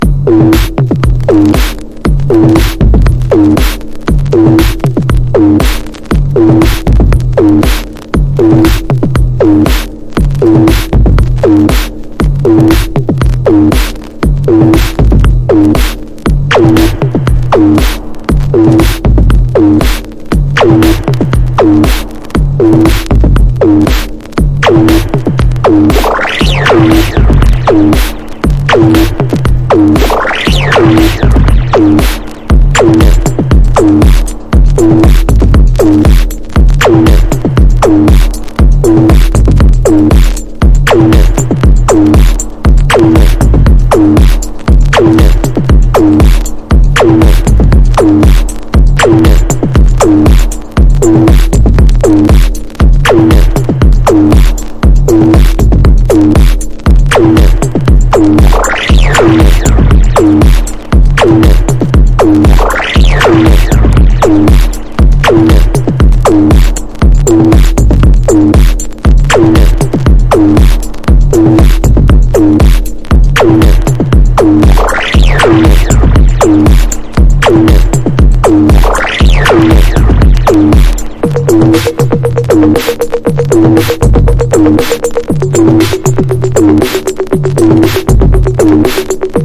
• HOUSE
# DEEP HOUSE / EARLY HOUSE# ELECTRO HOUSE / TECH HOUSE